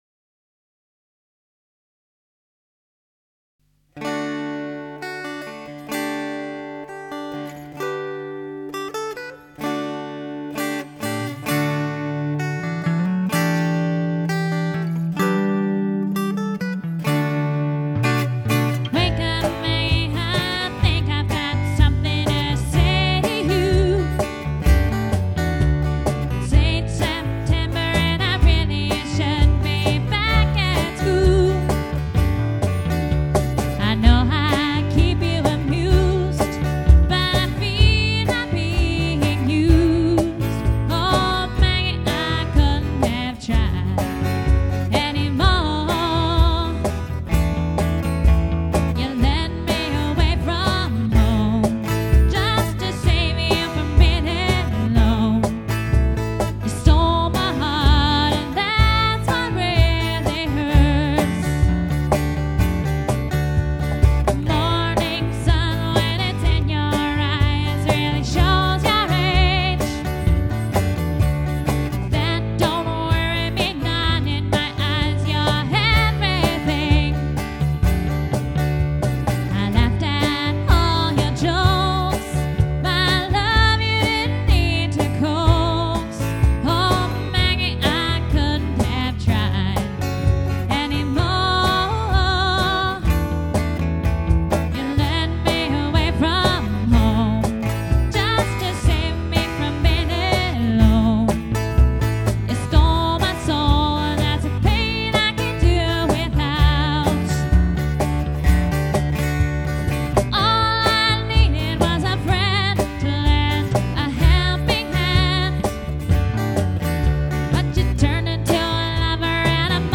Acoustic & Easy                      Listening